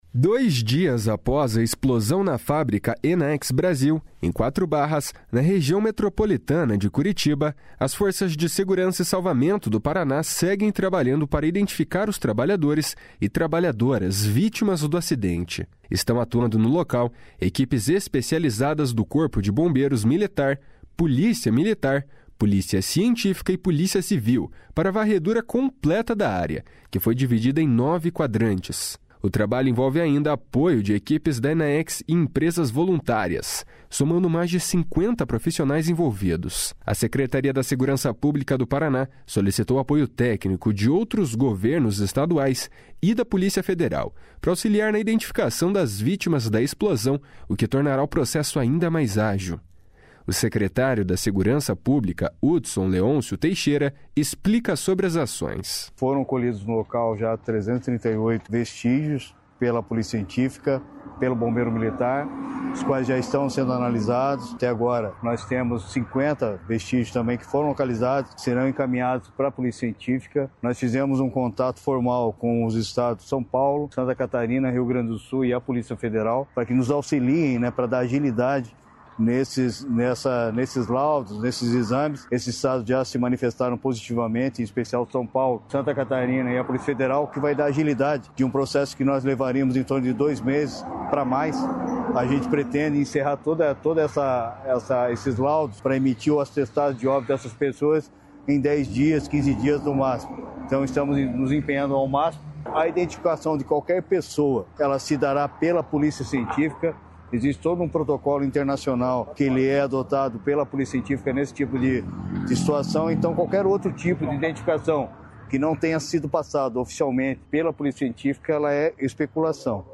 O secretário da Segurança Pública, Hudson Leôncio Teixeira, explica sobre as ações.